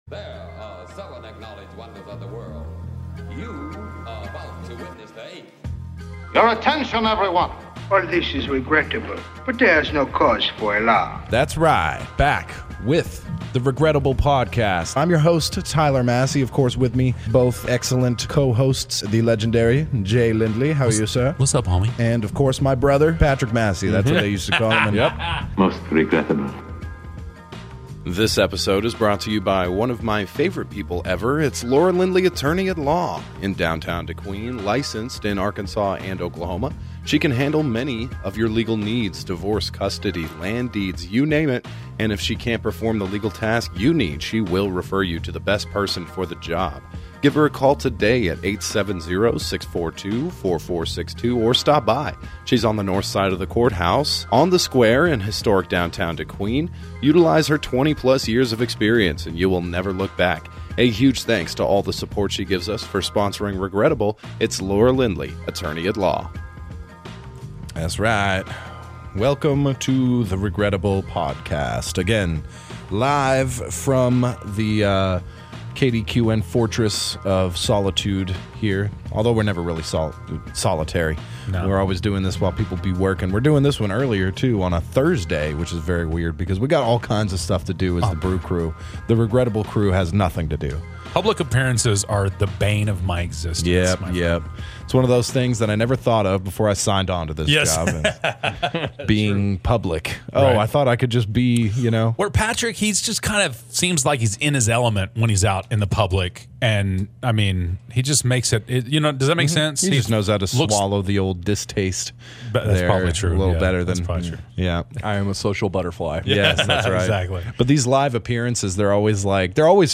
Disclaimer: Strong language, Sensitive subjects.